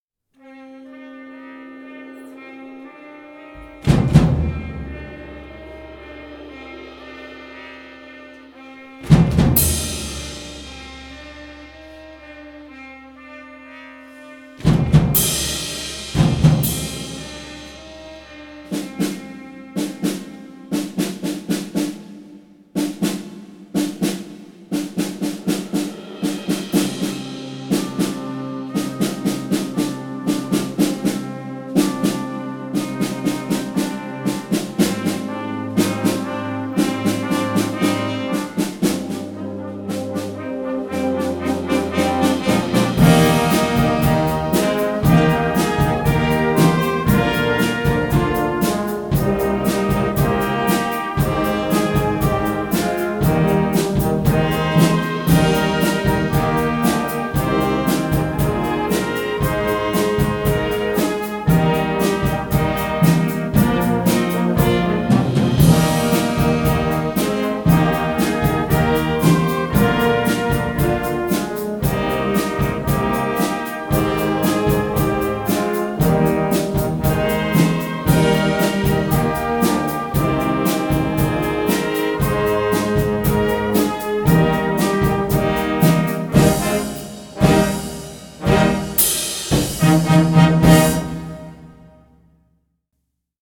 Gattung: Moderner Einzeltitel Jugendblasorchester
Besetzung: Blasorchester